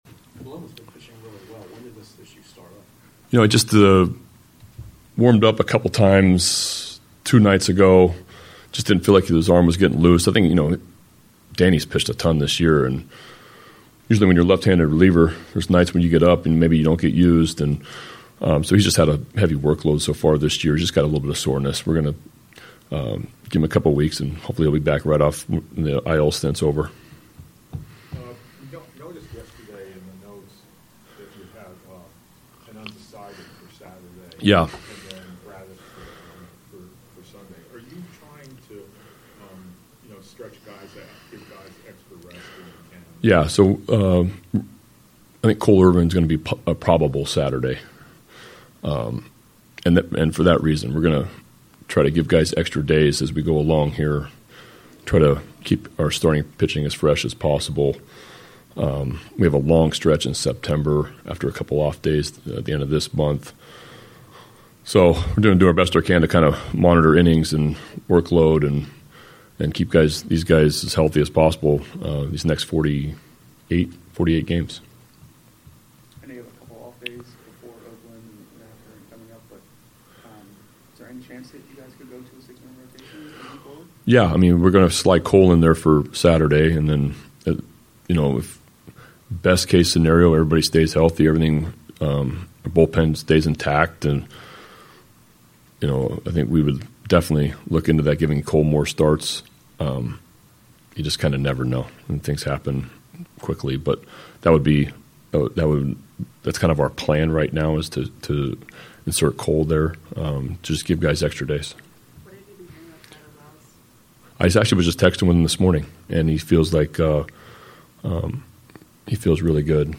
Brandon Hyde meets with media prior to Houston series finale
Locker Room Sound